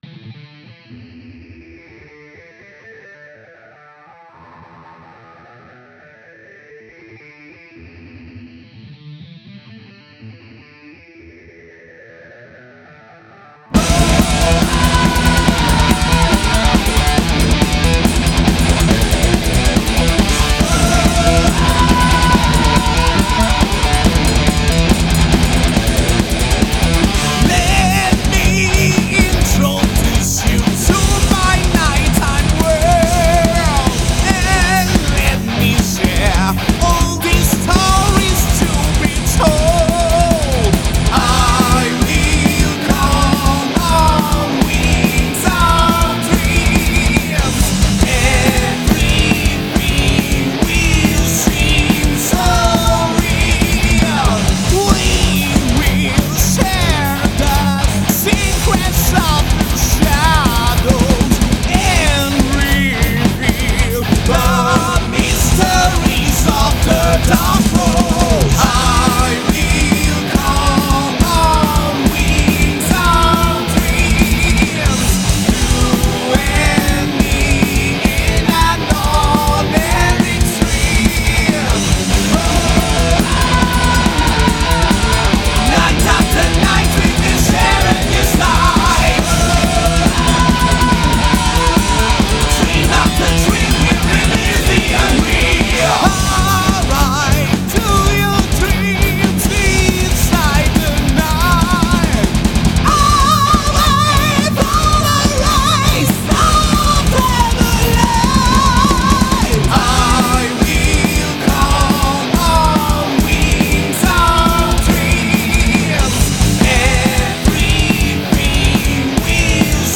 heavy metal France